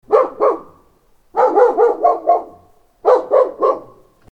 Tiếng chó sủa: